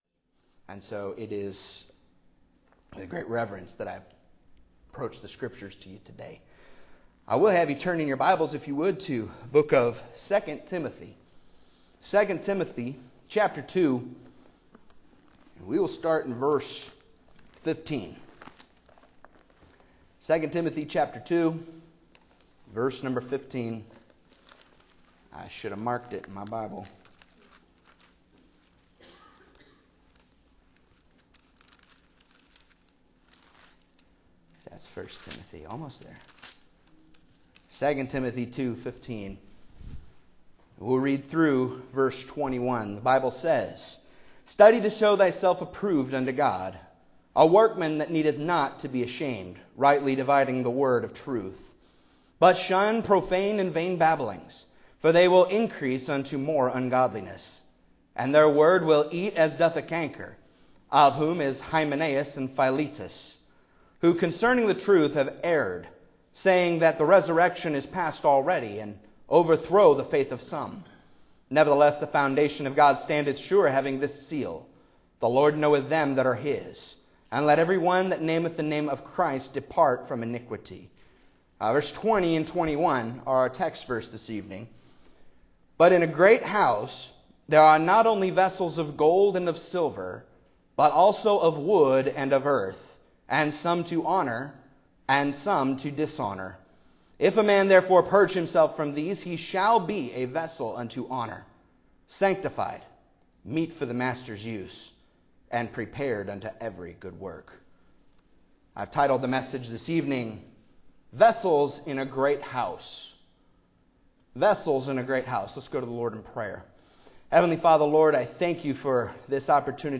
Passage: II Timothy 2:15-21 Service Type: Evening Service